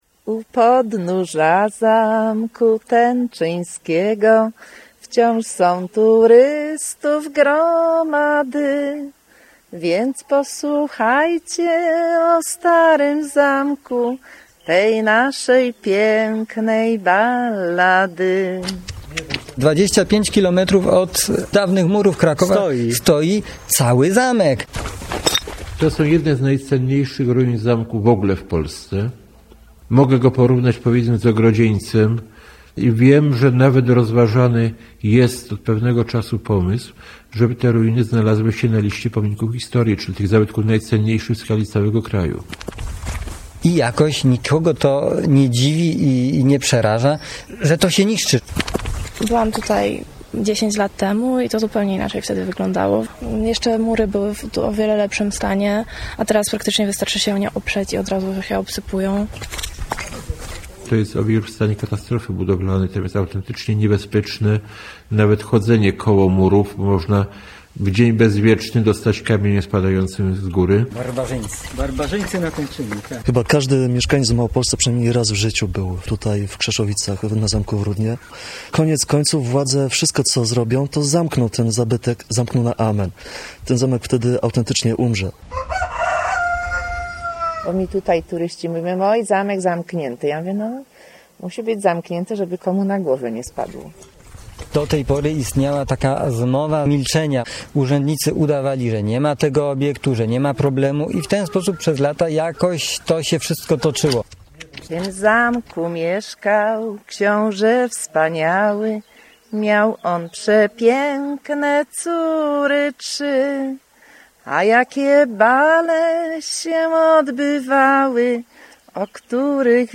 Ma ona na celu stałe przedstawianie krytycznej sytuacji Tenczyna jak również wskazywanie jego wartości historycznej i walorów turystycznych. W maju 2009 r. na antenie Radia Kraków nadany został reportaż pt. "Na łasce barbarzyńców" Reportaż ten był emitowany również na antenie Programu Trzeciego Polskiego Radia ................................................................................................................................................................